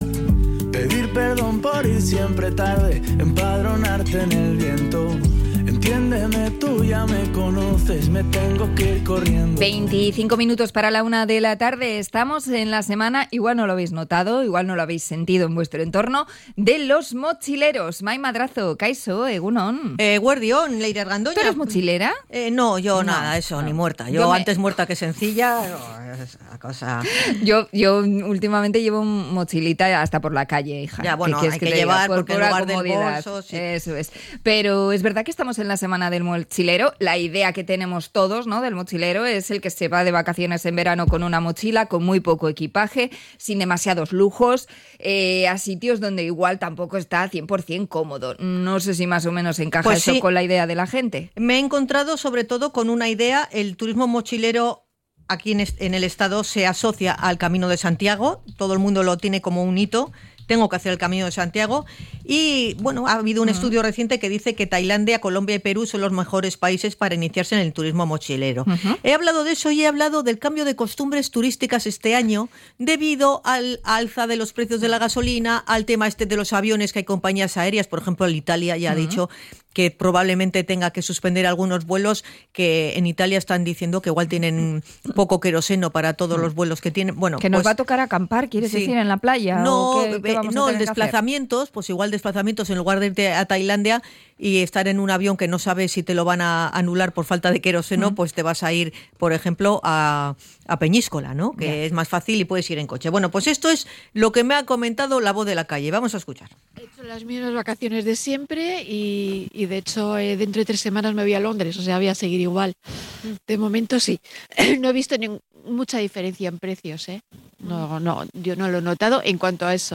Hemos salido a la calle para conocer la opinión de los viandantes sobre el encarecimiento del turismo